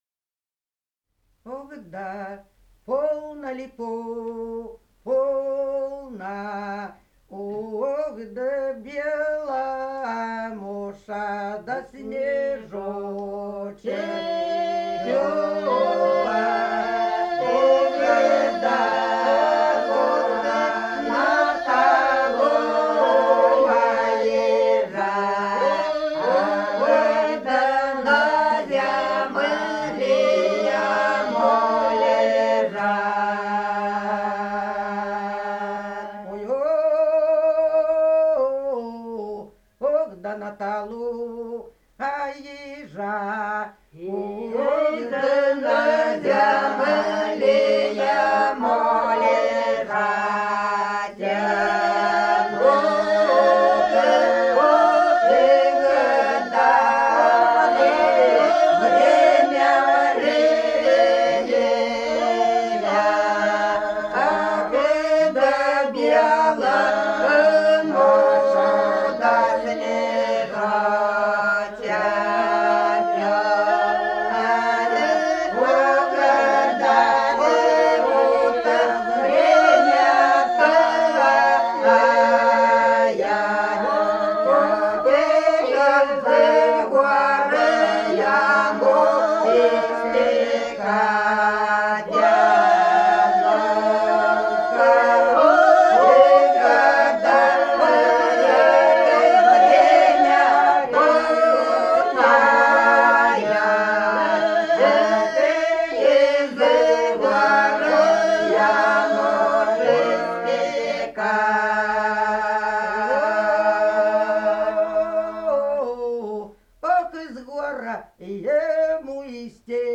Голоса уходящего века (село Подсереднее) Полно белому снежочку